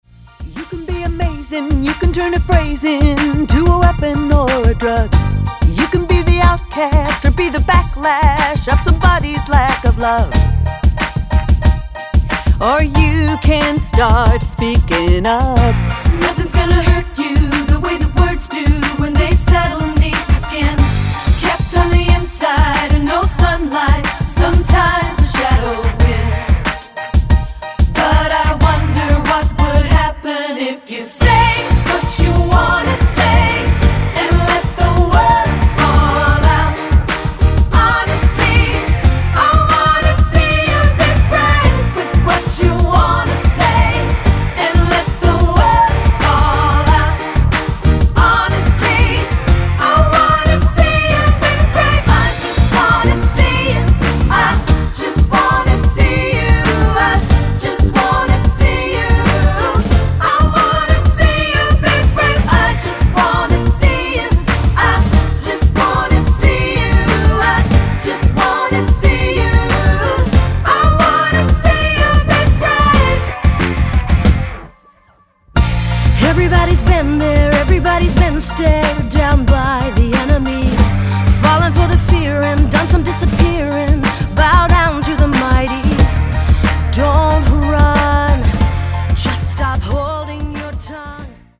Choral Early 2000's Pop